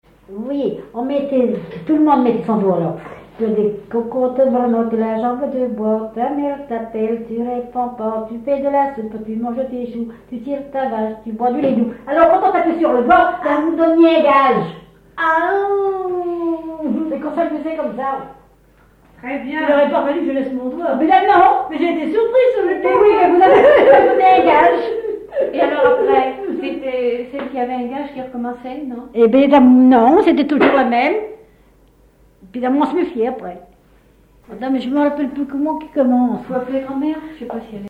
Thème : 0078 - L'enfance - Enfantines - rondes et jeux
Genre brève
Catégorie Pièce musicale inédite